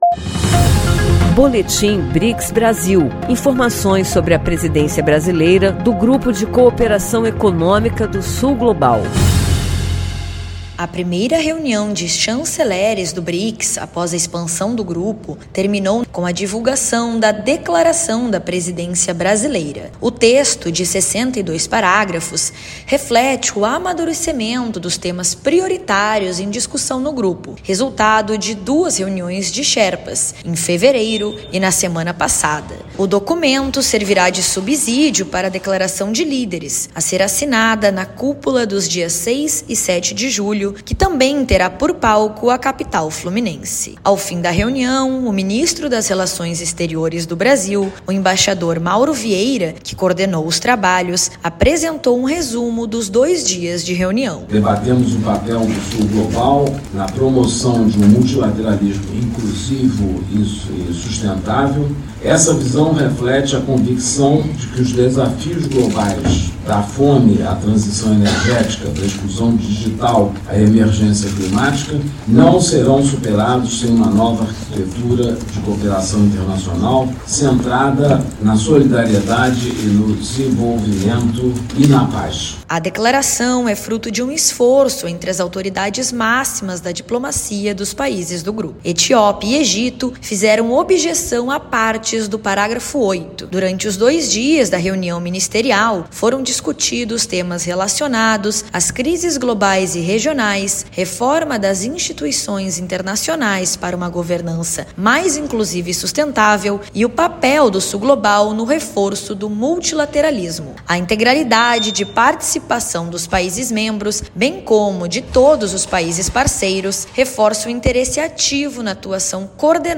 A Reunião, que contou com quorum completo entre países membros e parceiros, avançou em pautas que subsidiarão os líderes de Estado e governo do BRICS na Cúpula de julho. O ministro do Itamaraty, o embaixador Mauro Vieira, apresentou a Declaração da Presidência Brasileira. Ouça a reportagem e saiba mais.